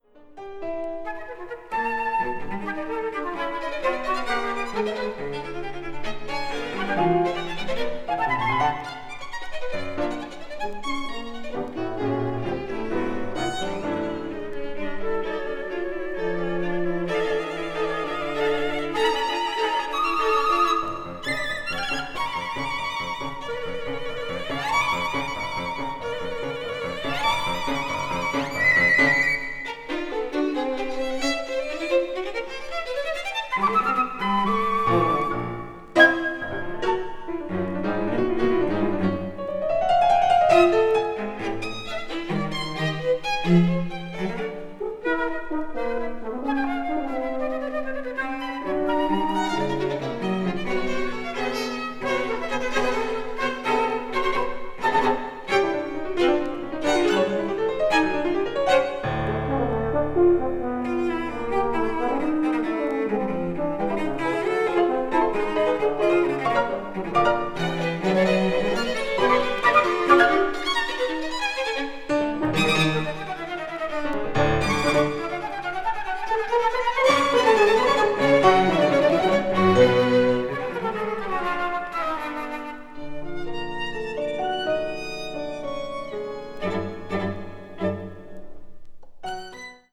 media : EX-/EX-(わずかなチリノイズ/軽いチリノイズが入る箇所あり)